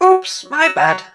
barley_throw_01.wav